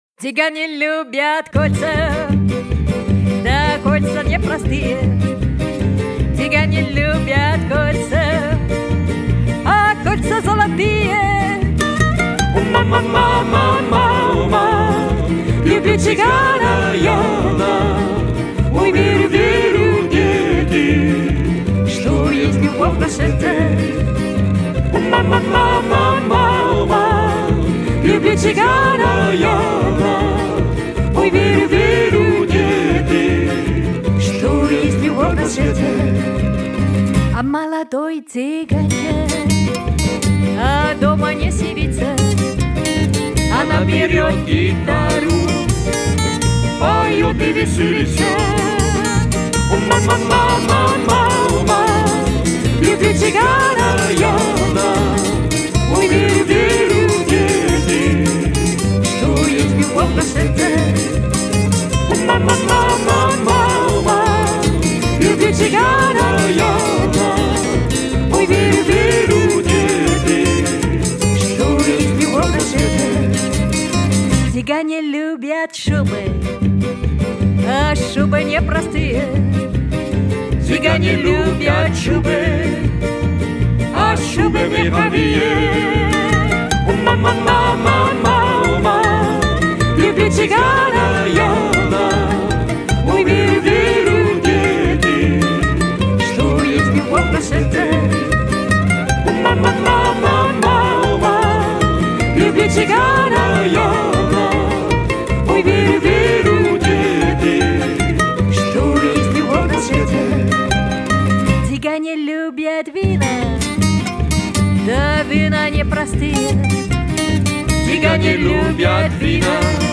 LES CHANSONS RUSSES :
Tsiganje ljubjat (Chant tsigane) :